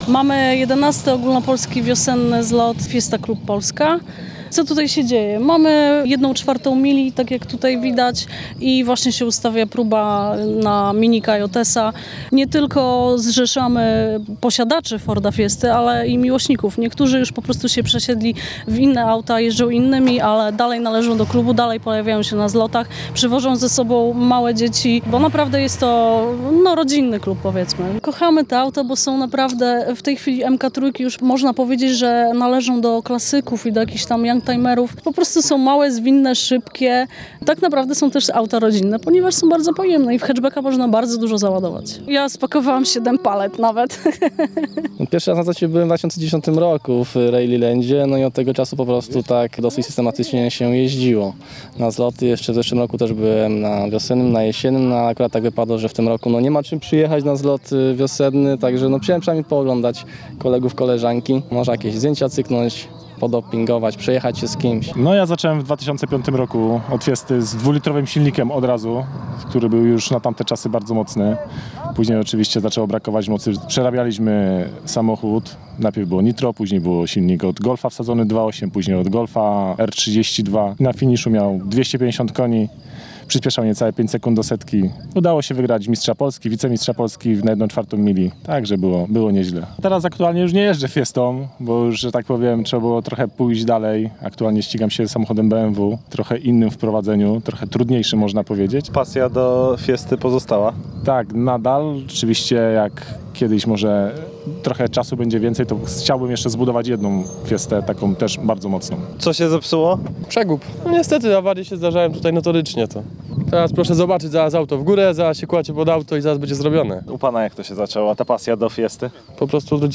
Link do reportażu
reportaz_zlotowy_2015.mp3